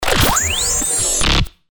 FX-1580-SCROLLER